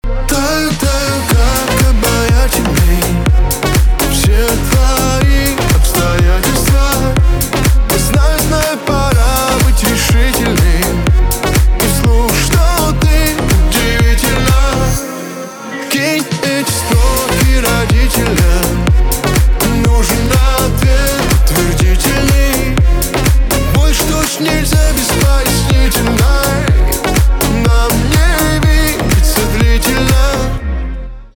поп
ремиксы , битовые , басы , качающие
чувственные , романтические